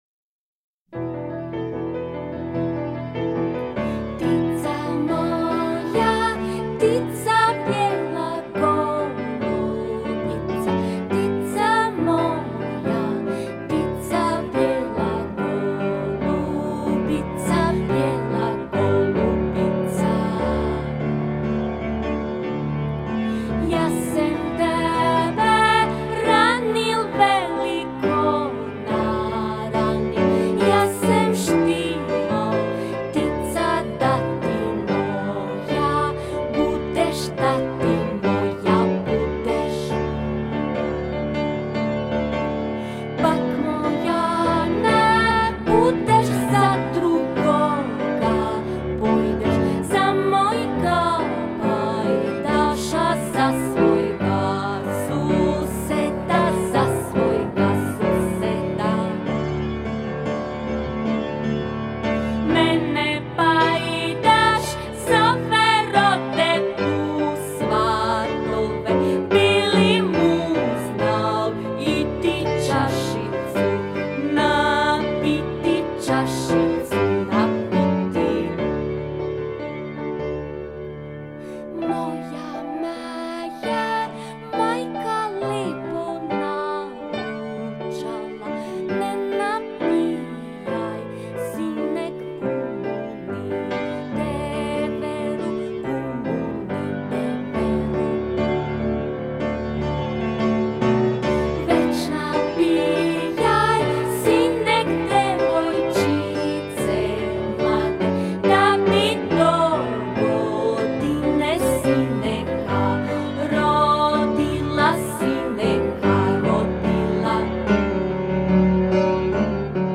glas i gitara
glasovir i udaraljke
cimbale